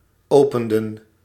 Ääntäminen
Ääntäminen Tuntematon aksentti: IPA: /ɔʊpənːdə/ Haettu sana löytyi näillä lähdekielillä: hollanti Käännöksiä ei löytynyt valitulle kohdekielelle.